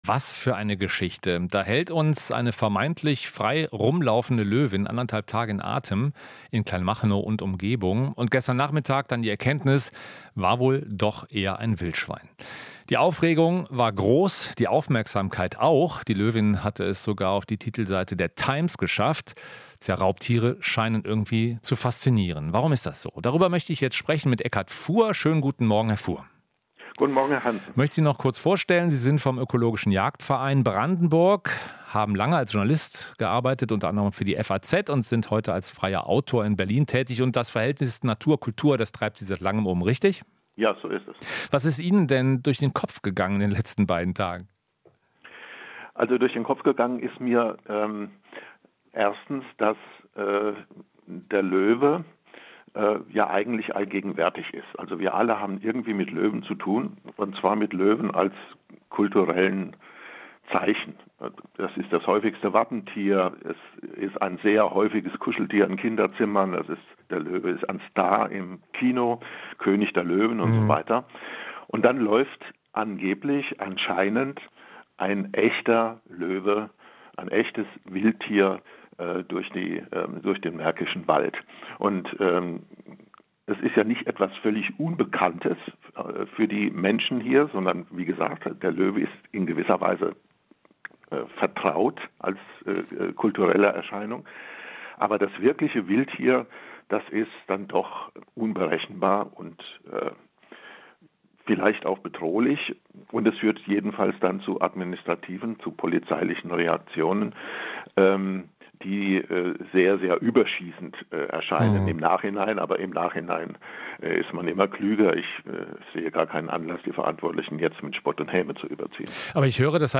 Aber es war ein Schwein - Kultur- und Jagdexperte: Der Löwe ist allgegenwärtig